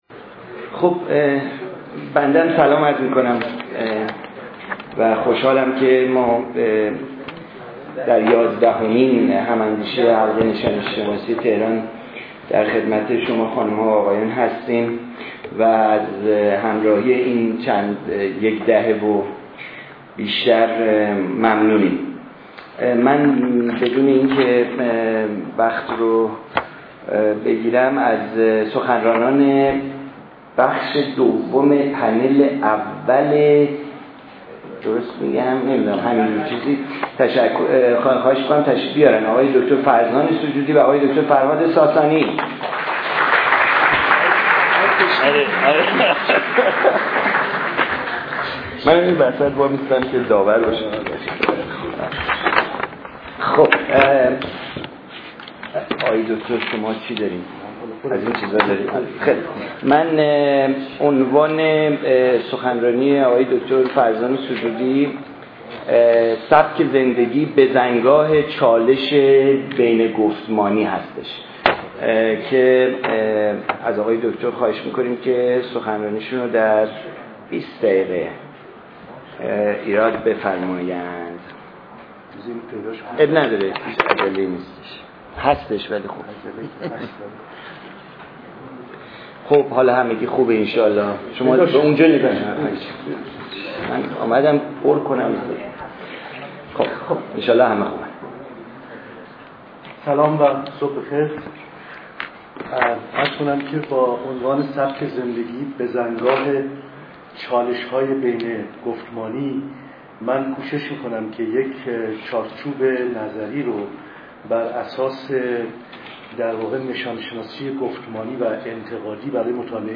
این همایش اسفند ماه ۹۳ در مرکز دایرهالمعارف بزرگ اسلامی برگزار شد.